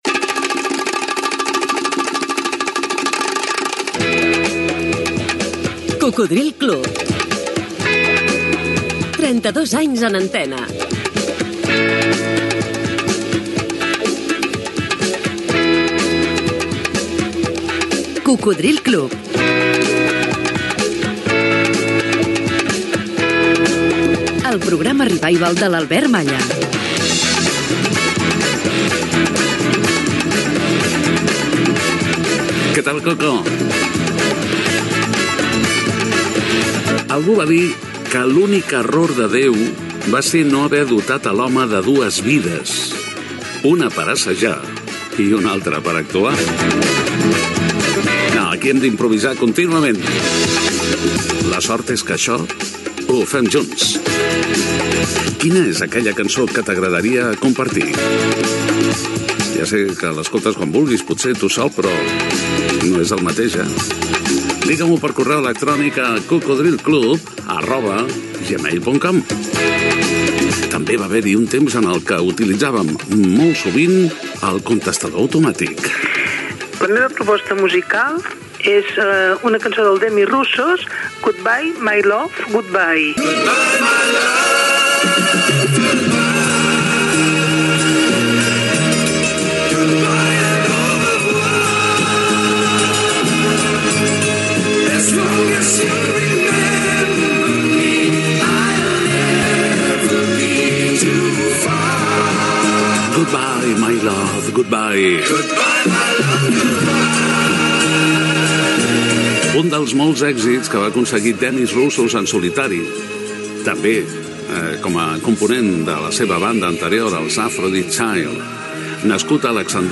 Careta del programa, presentació, demanda d'una oïdora, tema musical, indicatiu, nombre d'emissores que emeten el programa, correu d'una oïdora i tema musical
Musical
FM